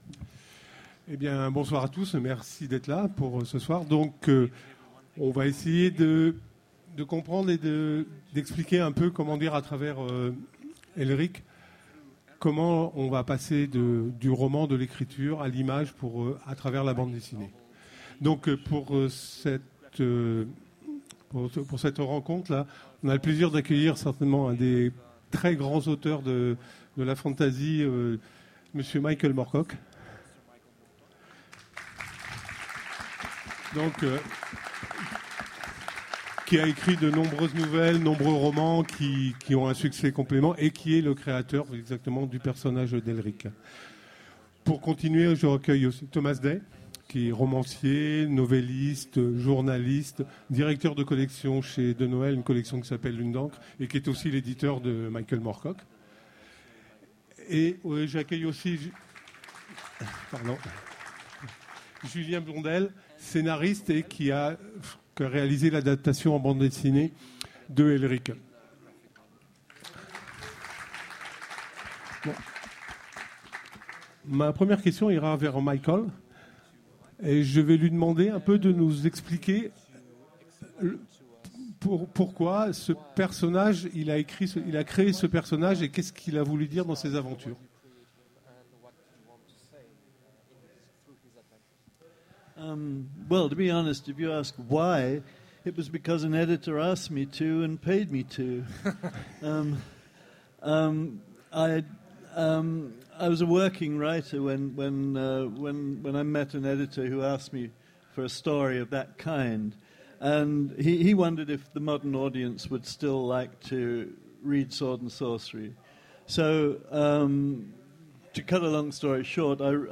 Mots-clés Elric Conférence Partager cet article